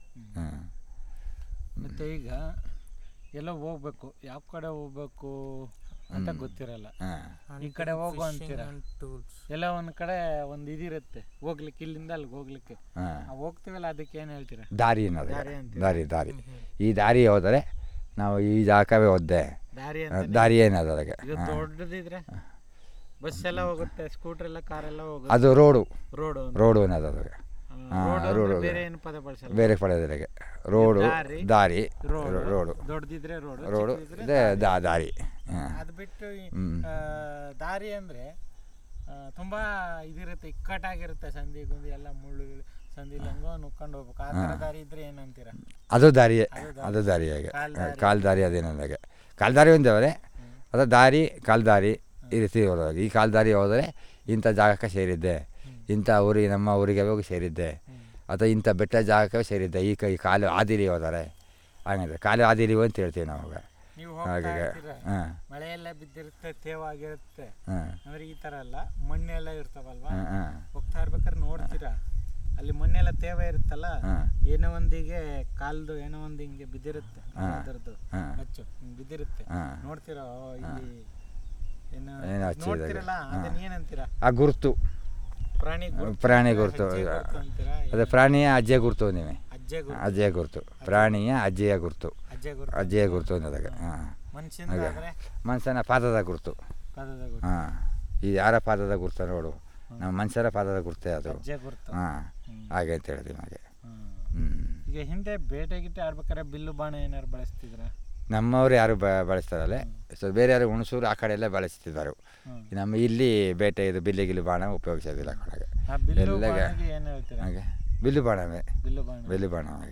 Elicitation of words about Hunting, fishing and its tools